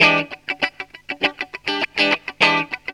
GTR 62 EM.wav